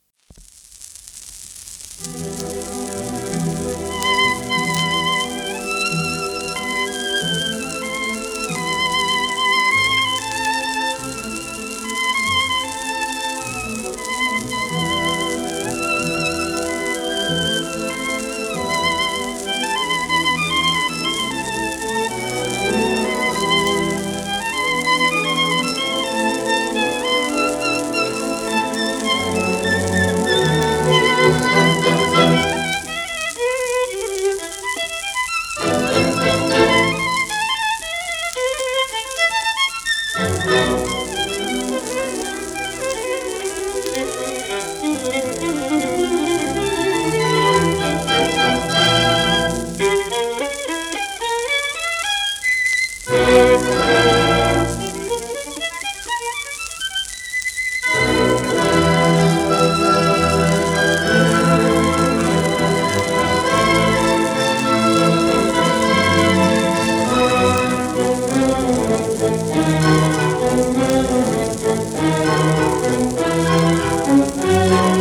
盤質A- *小キズ,軽度盤反り
1933年録音